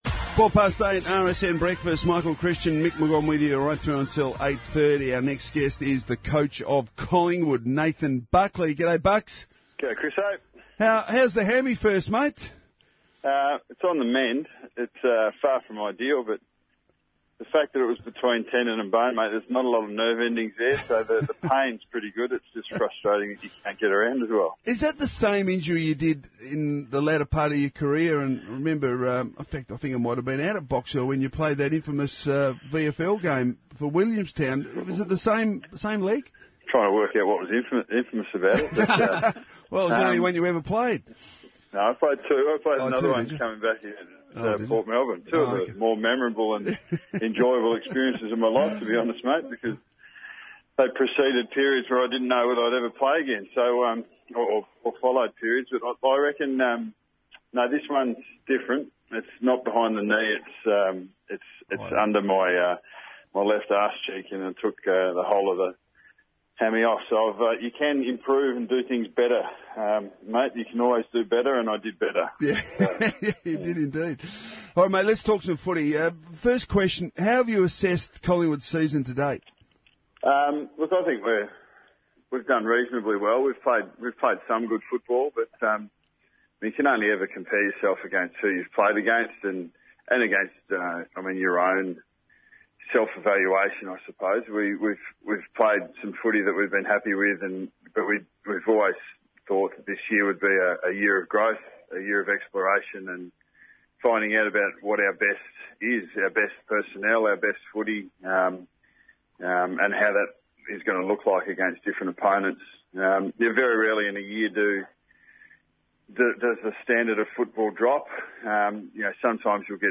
Listen to coach Nathan Buckley chat with former Collingwood teammates Michael Christian and Mick McGuane on Radio Sports National on Thursday 11 June.